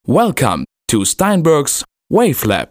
原始素材：（选自WaveLab的一段人声）
下载原始人声welcome.mp3（44K）